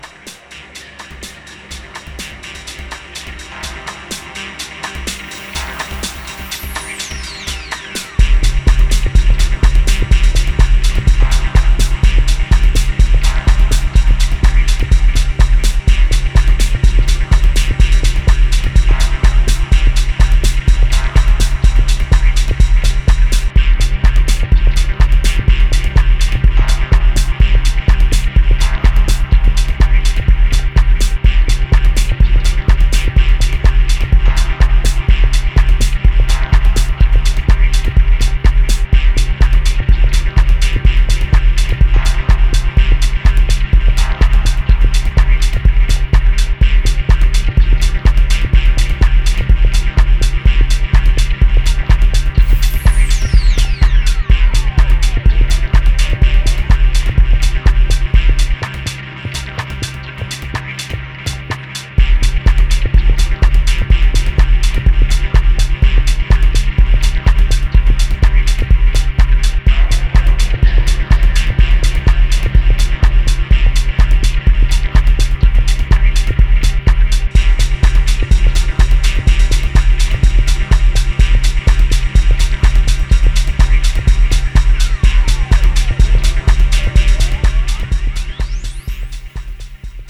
彼の地伝統のダブテックマナーなテクスチャーで綴られたディープ・ハウスチューン